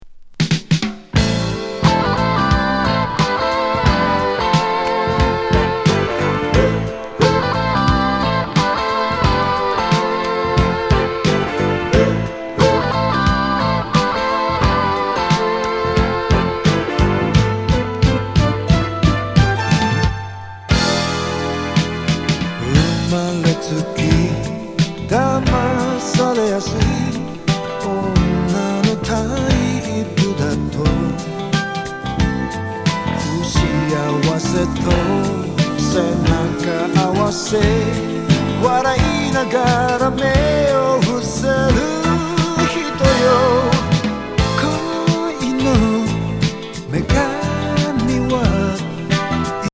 沖縄出身シンガー82年作。